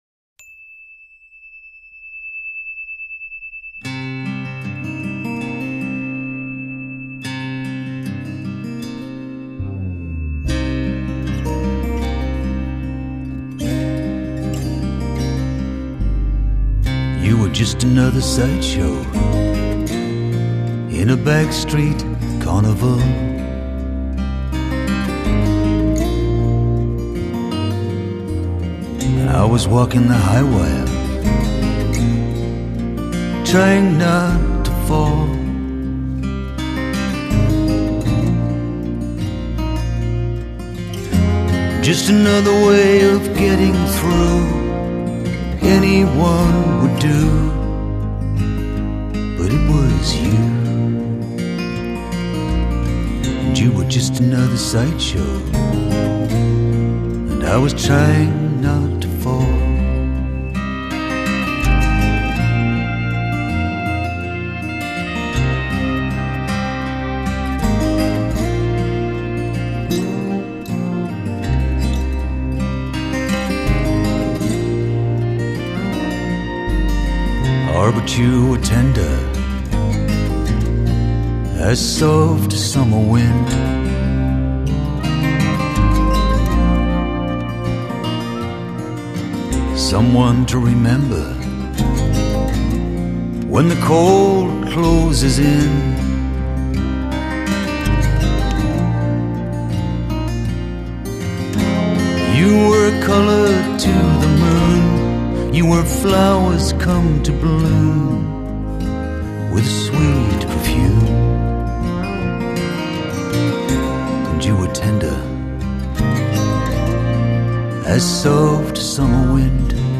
耐聽優美的曲調
與穩健優雅的吉他演奏
这张唱片的吉他录制都充满了柔软的弹性与充满金属光泽的美感，与充满空间中的残响。
录音精致、清澈而透明。